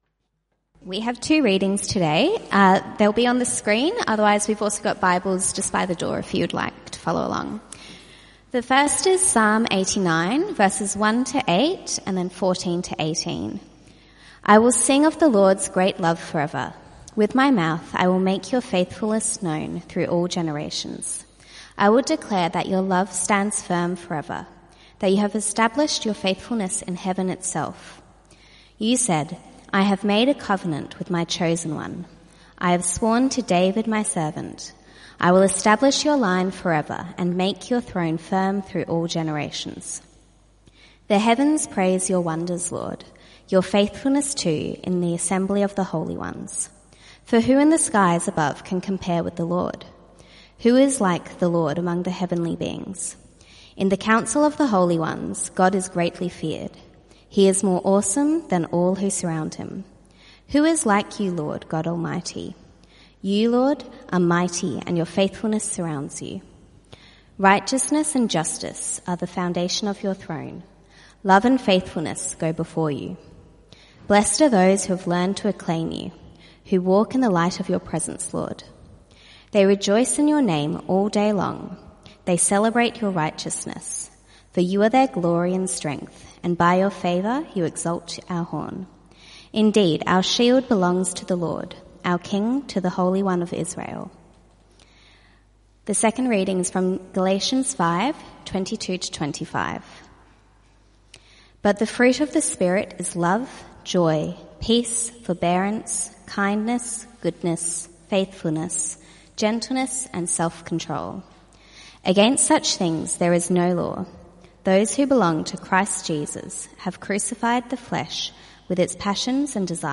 Type: Sermons